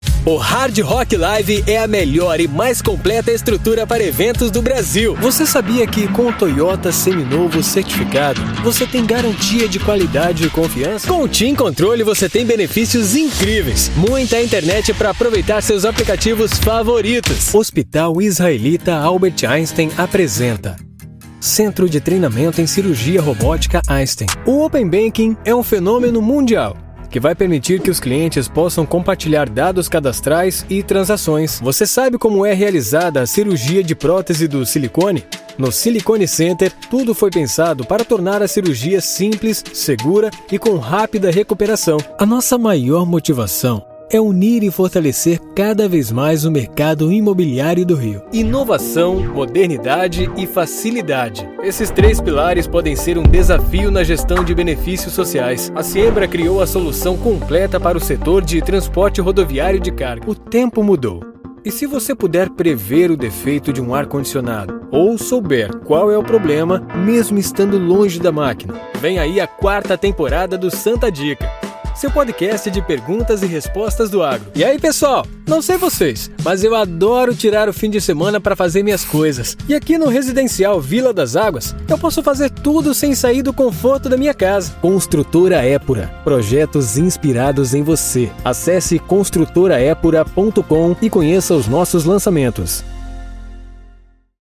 All voiceovers are recorded in an acoustic booth, resulting in clean audio free of any type of interference.
Sprechprobe: Sonstiges (Muttersprache):